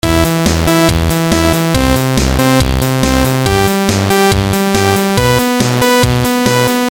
技术电子沟槽循环
描述：迅速制作了这些，节奏感强的电子乐循环。
Tag: 140 bpm Techno Loops Groove Loops 1.15 MB wav Key : Unknown